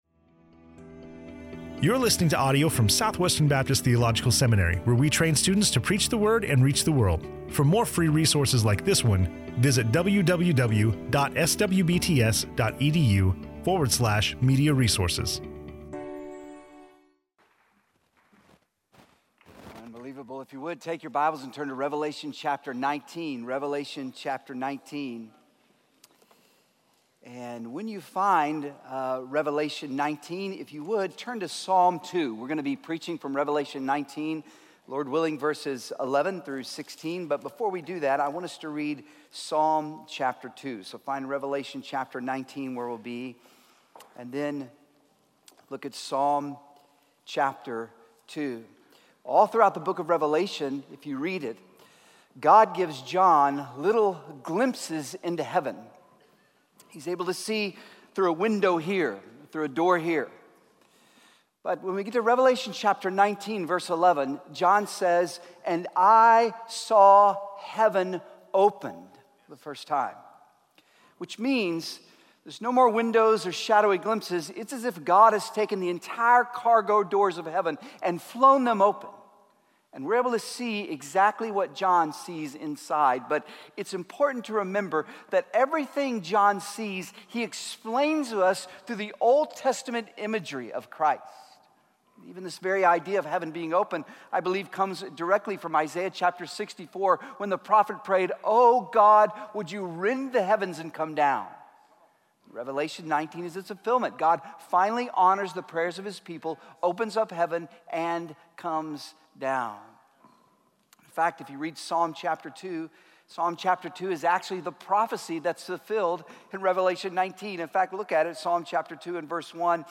Revelation 19:11-16; Psalm 2 in SWBTS Chapel on Thursday April 23, 2015